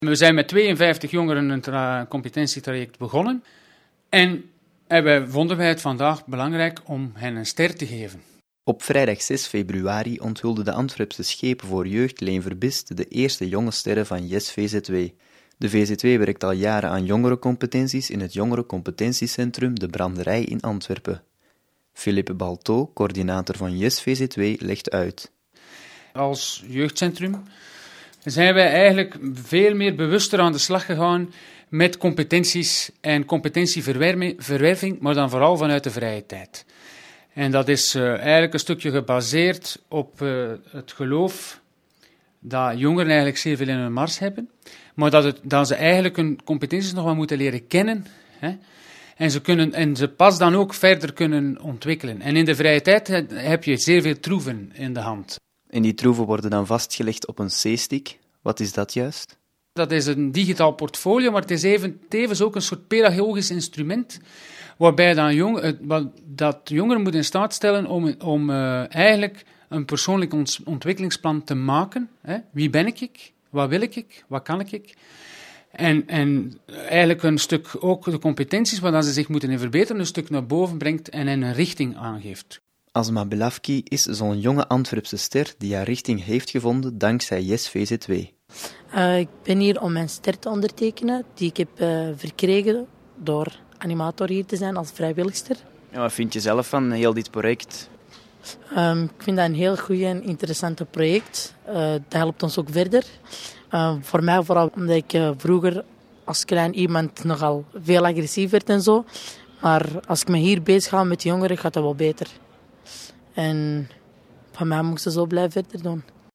Een gesprek